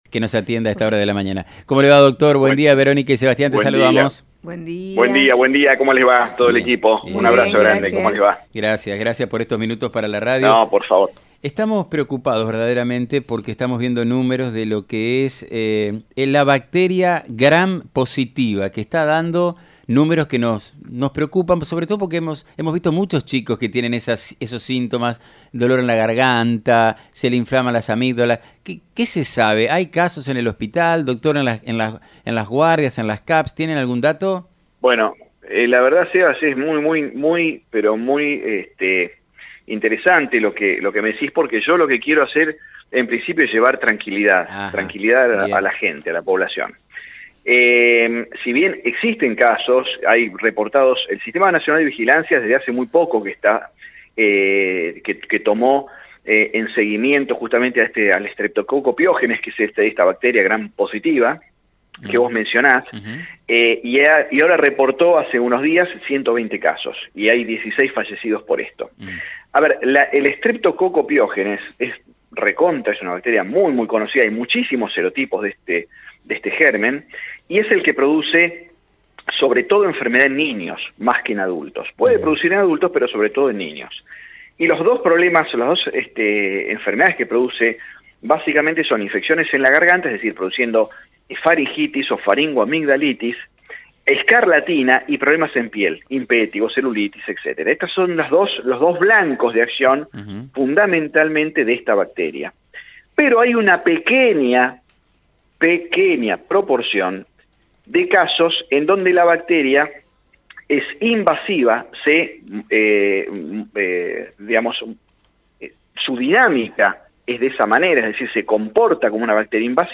En charla con